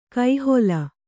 ♪ kai hola